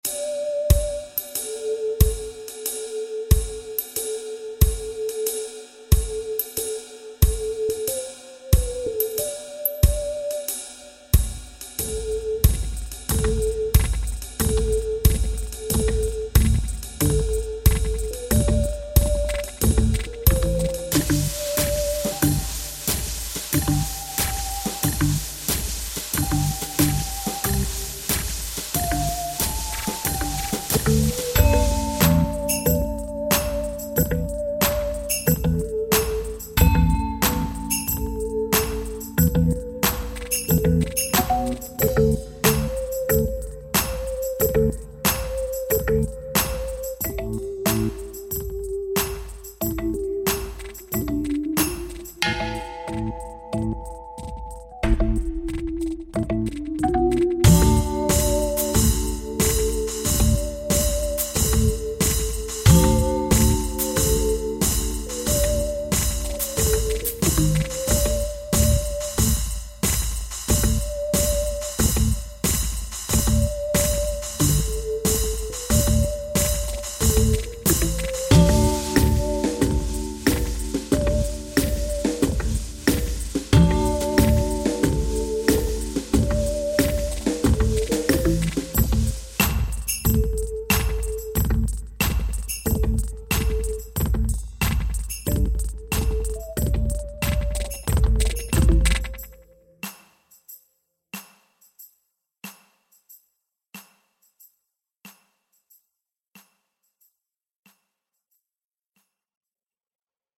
Sequel 3 samples